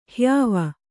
♪ hyāva